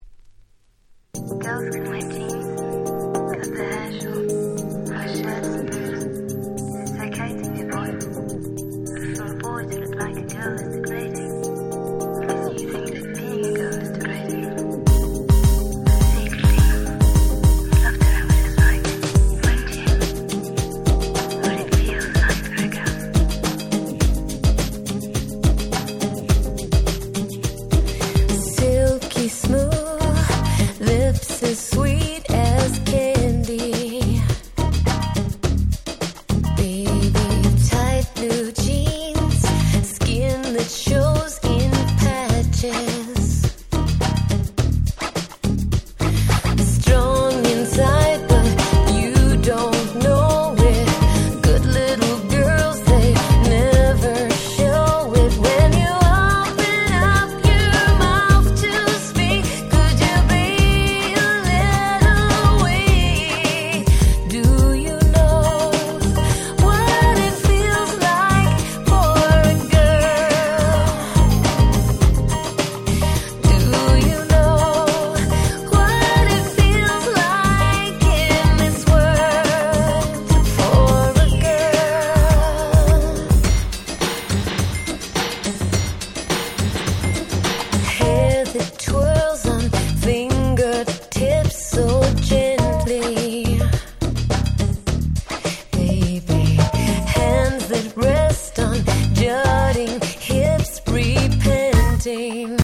01' Smash Hit Pops / R&B !!
華やかで温かい素敵なR&Bチューン！！